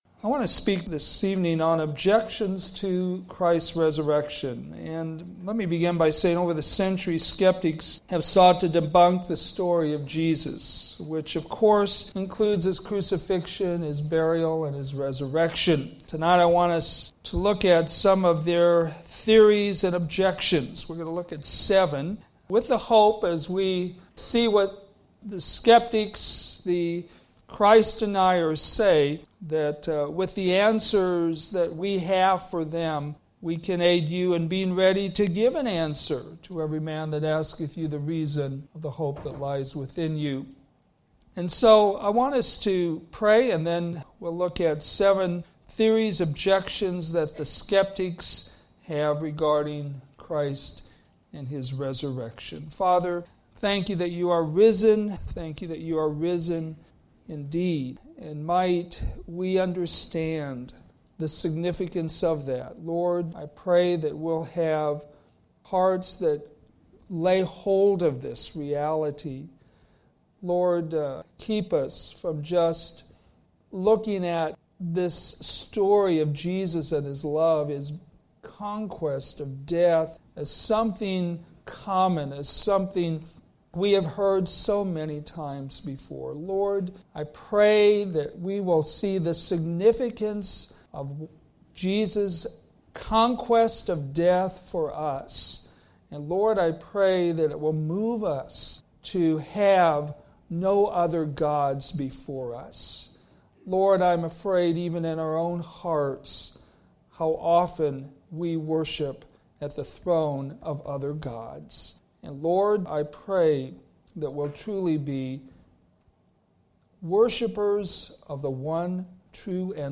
Sermons based on New Testament Scripture